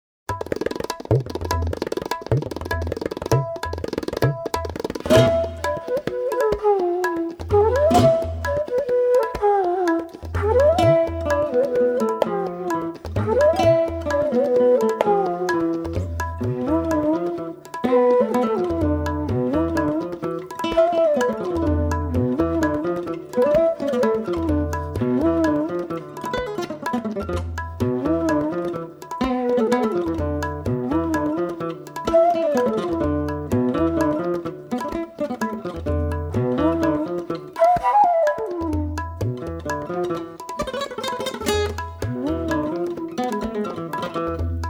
Genre: World Fusion.
bansuri
guitar
drums & percussion
tabla
double bass & electric bass
Recorded at Livingstone Studios, London